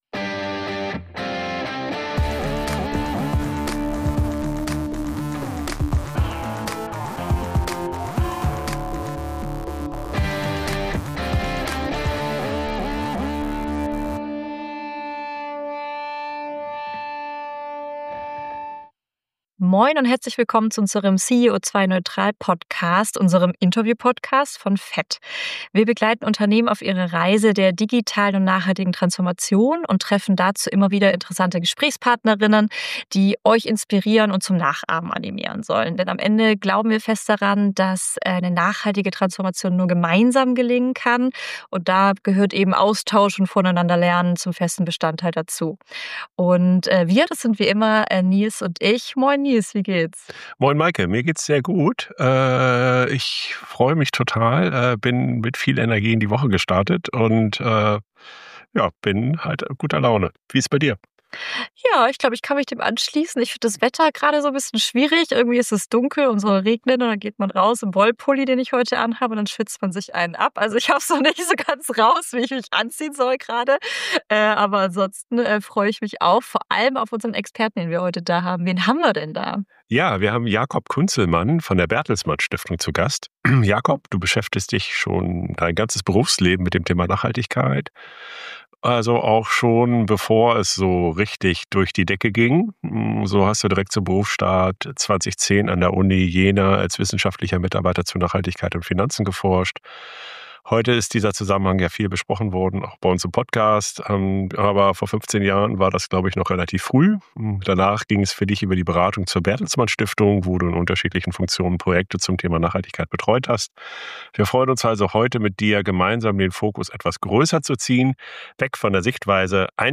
Die Stimme der Wirtschaft - der Sustainability Transformation Monitor 2025 der Bertelsmann Stiftung ~ CEO2-neutral - Der Interview-Podcast für mehr Nachhaltigkeit im Unternehmen Podcast